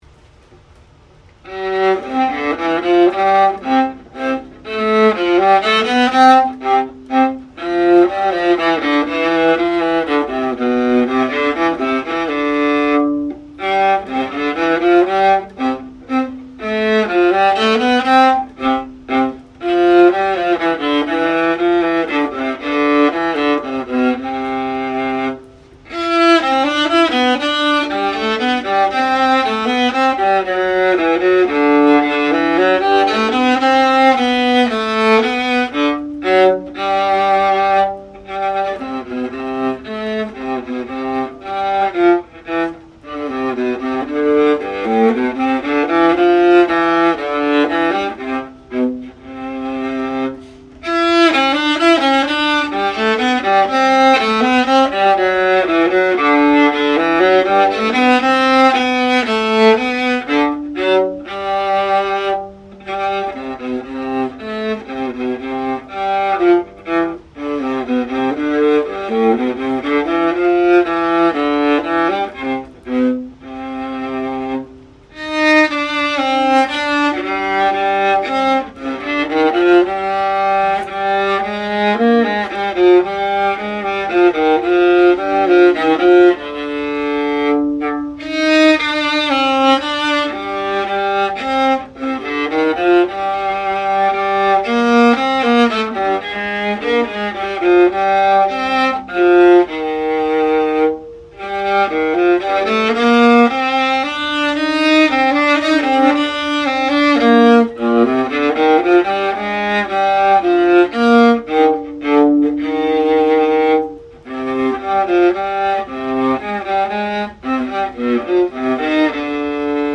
The AMAZING ChinCello - a Cello played like a Violin!
an unrehearsed
iPhone recording of Bach Minuet played on the Artista ChinCello.